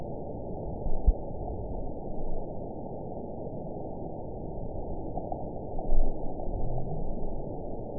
event 921926 date 12/22/24 time 21:29:41 GMT (5 months, 3 weeks ago) score 9.04 location TSS-AB03 detected by nrw target species NRW annotations +NRW Spectrogram: Frequency (kHz) vs. Time (s) audio not available .wav